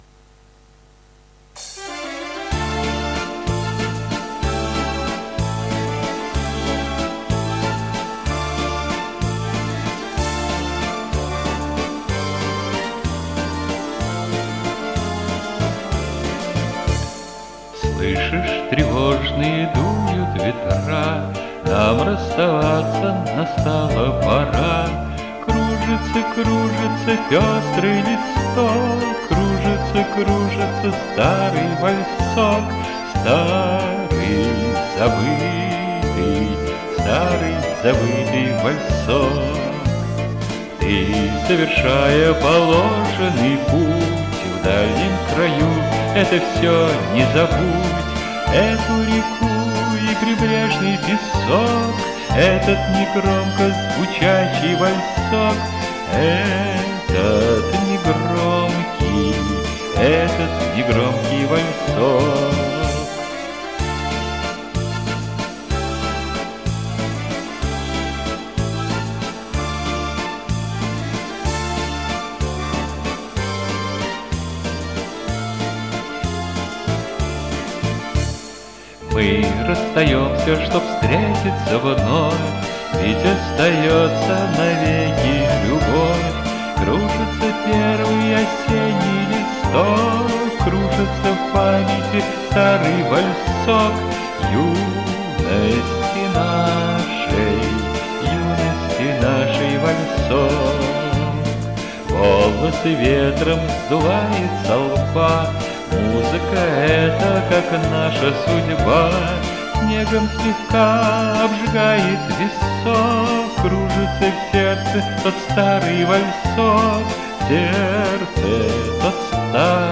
У правого - качество звучания выше.
гРрррассирующая "Р".